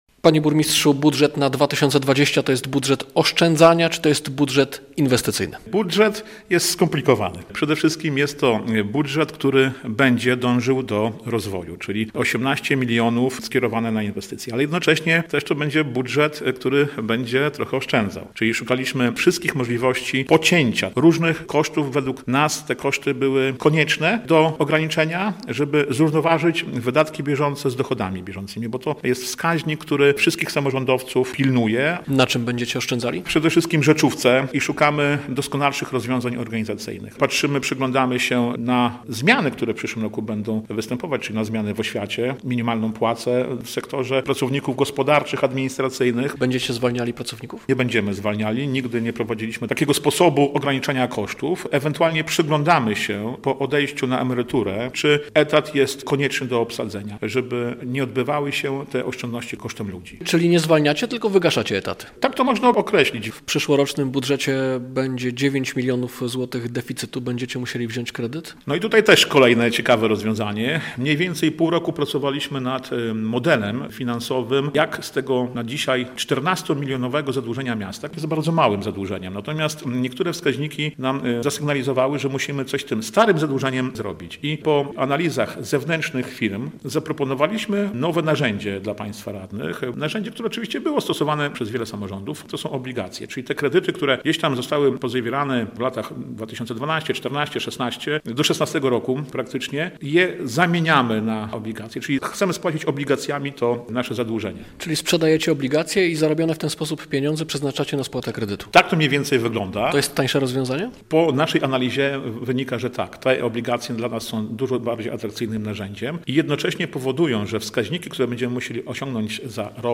Radio Białystok | Gość | Dariusz Latarowski - burmistrz Grajewa